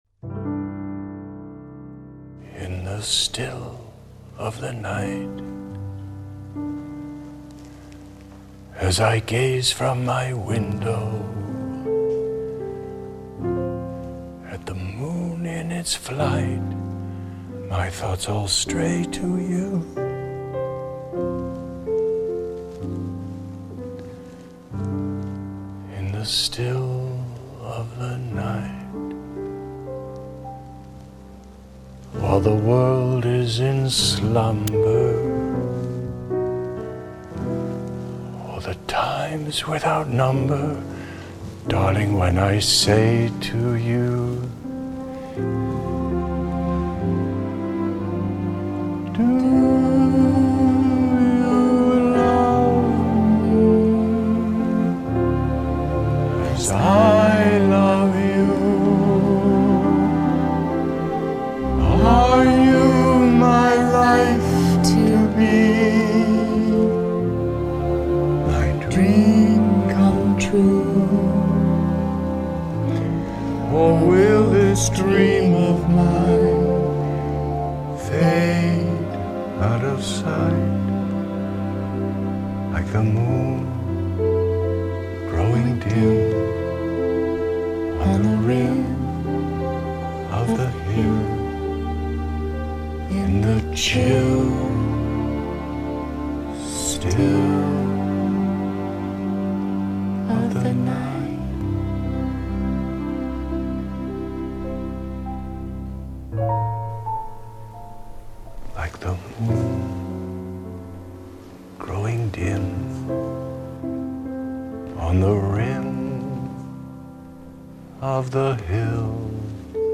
1937   Genre: Soundtrack   Artist